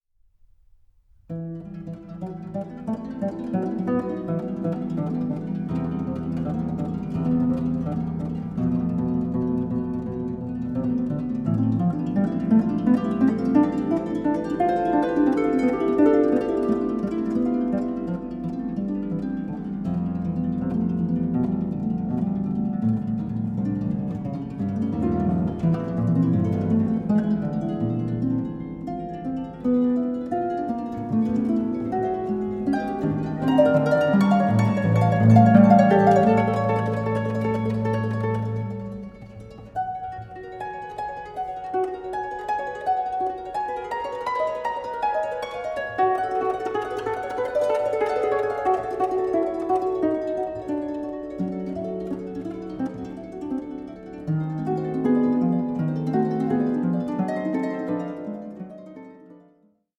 for harp